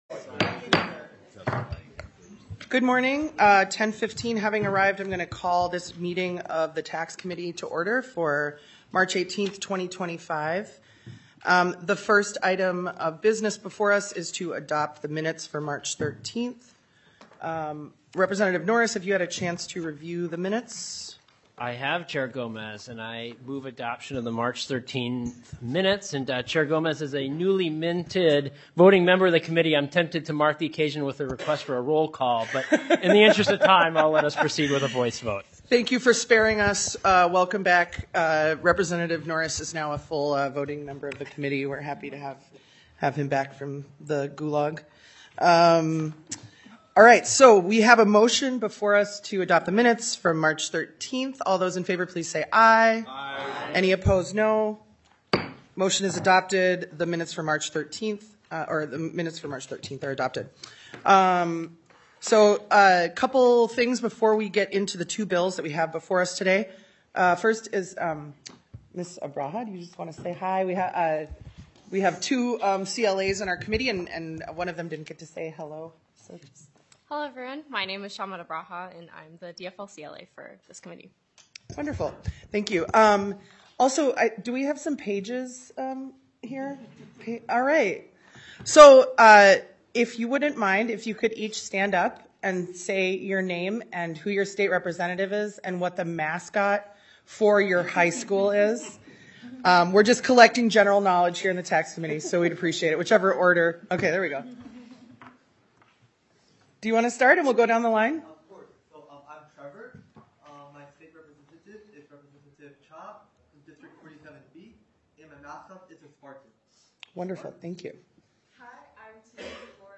Taxes FIFTEENTH MEETING - Minnesota House of Representatives